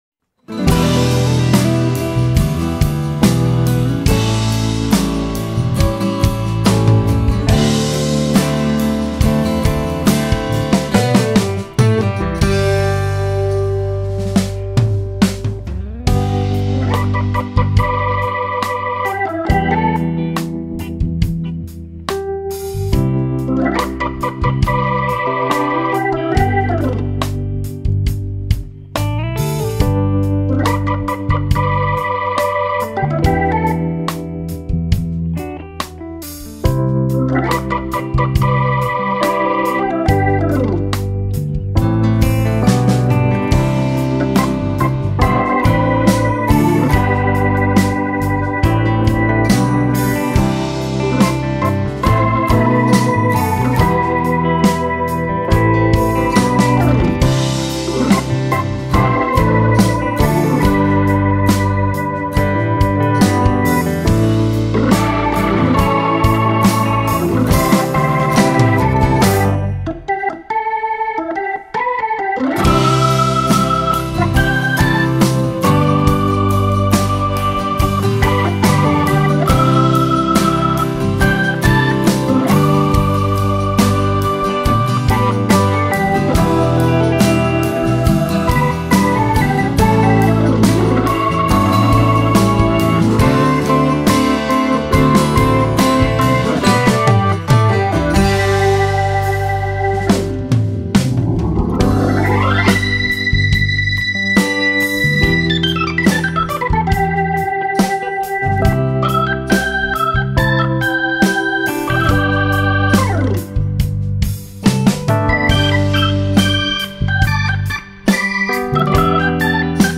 Помогите опознать инструменталку
По звучанию похоже на 70-е годы, но возможно и современная
Орган Хаммонд.
hammond.mp3